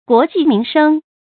guó jì mín shēng
国计民生发音
成语正音 国，不能读作“ɡuǒ”。